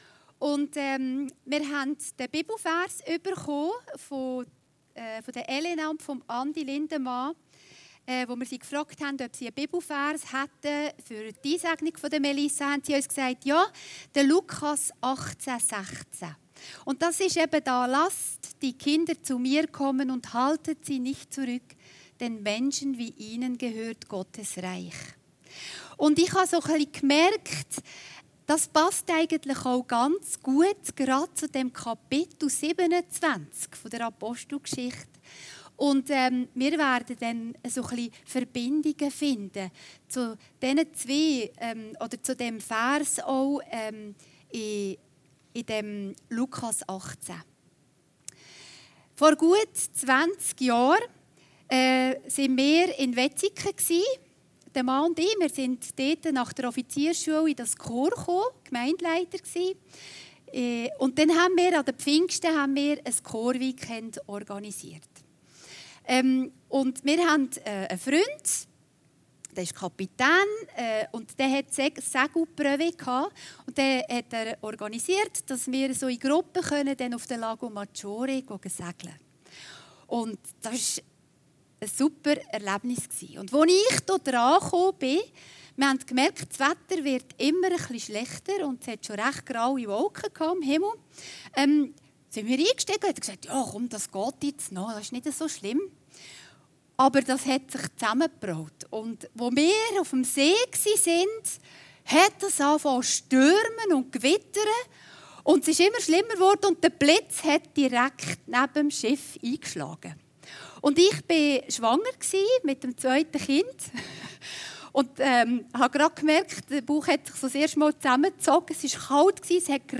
Predigten Heilsarmee Aargau Süd – Einsegnung